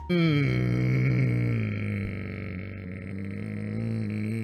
pewdiepie-hmmm-1.mp3